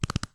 Horse Gallop 3.wav